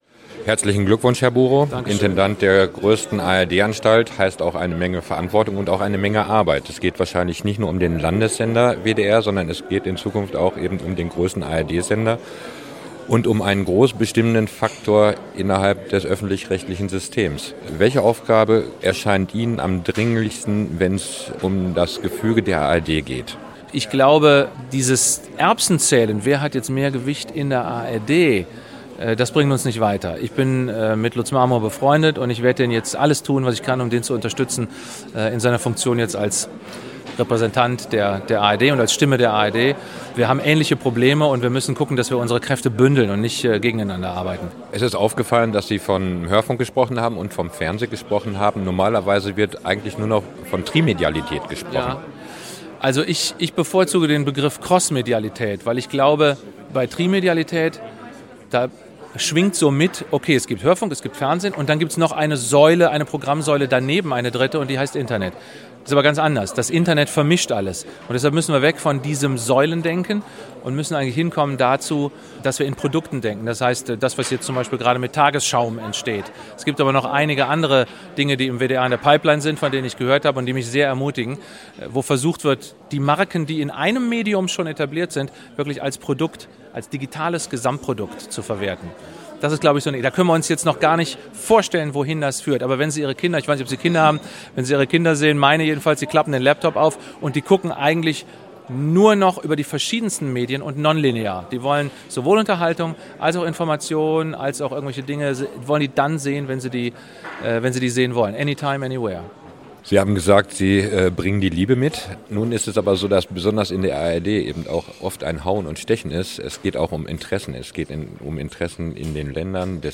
* Tom Buhrow, tagesthemen-Moderator und neuer WDR-Intendant
Was: Interview zur WDR-Intendantenwahl
Wo: Köln, WDR-Funkhaus am Wallrafplatz, Kleiner Sendesaal